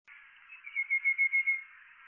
紅頭穗鶥 Stachyris ruficeps praecognita
高雄市 鼓山區 柴山
錄音環境 雜木林
鳴唱
Sennheiser 型號 ME 67
鳥